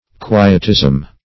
Quietism \Qui"et*ism\, n. [Cf. F. qui['e]tisme.]